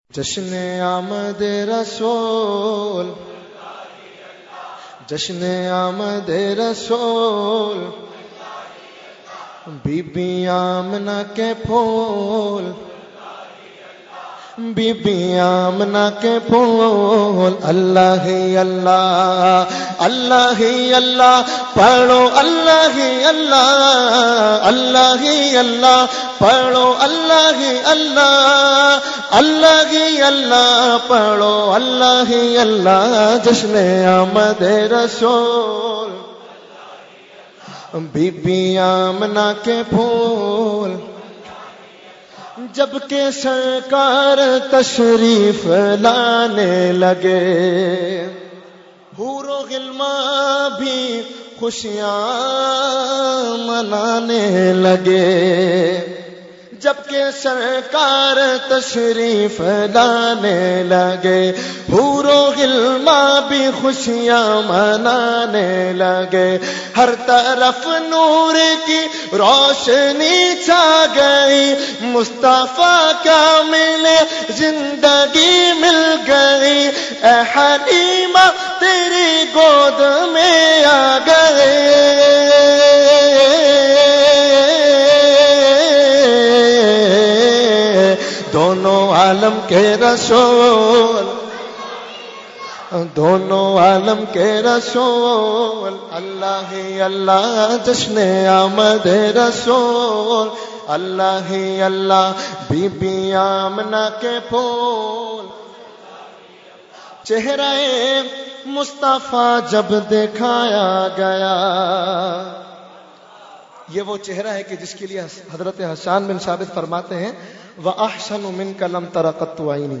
Category : Naat | Language : UrduEvent : Jashne Subah Baharan 2018